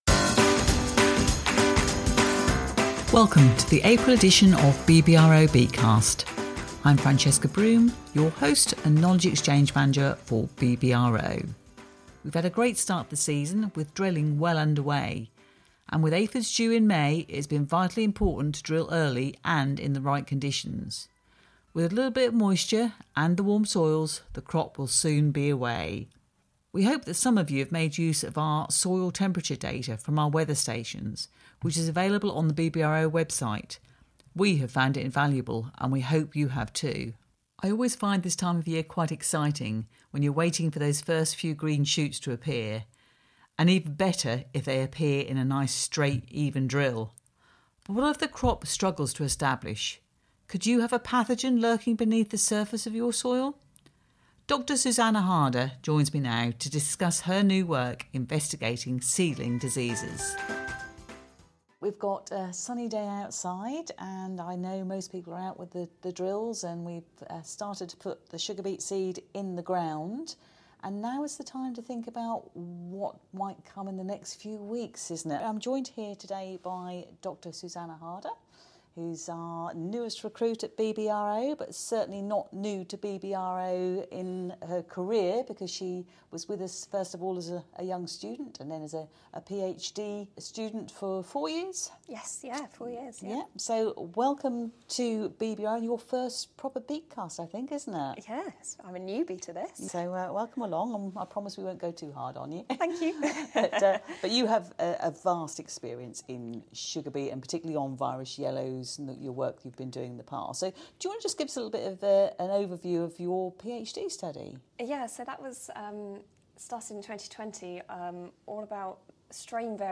As many experienced harvester drivers will tell you there is a lot more to harvesting a good beet crop than you'd think. We spend some time in the cab with a new driver and carry out a harvester test to really put him through his paces - will he pass muster?!